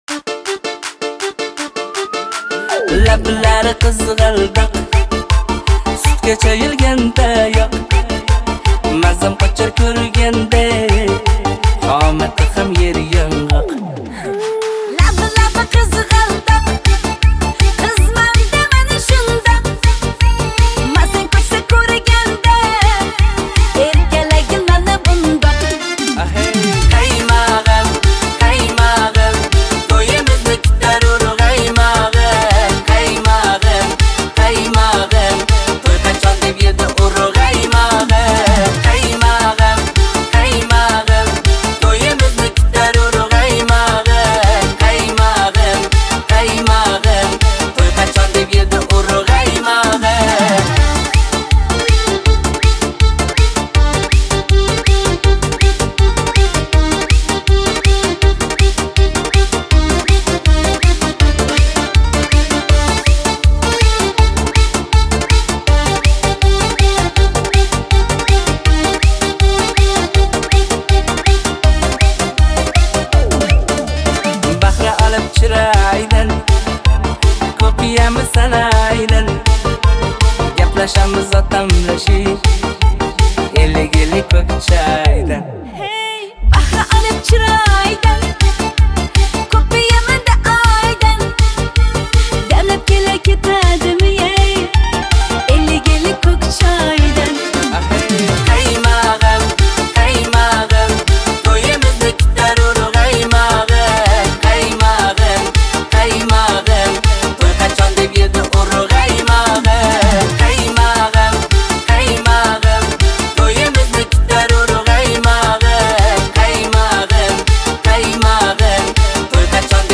• Узбекские песни